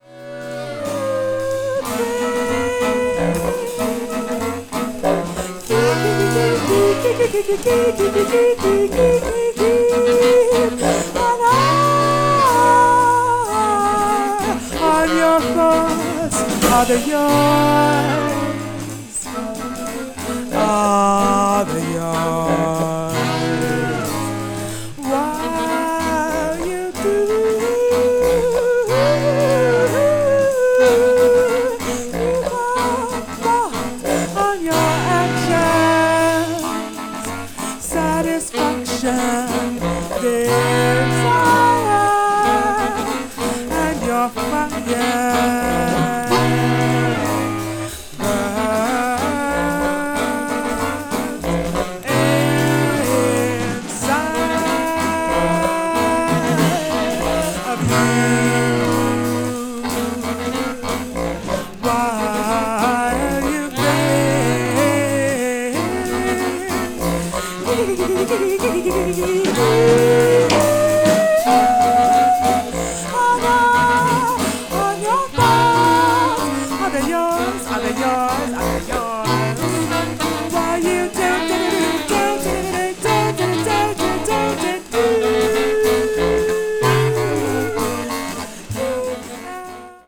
media : EX/EX(わずかにチリノイズが入る箇所あり)
avant-jazz   free improvisation   free jazz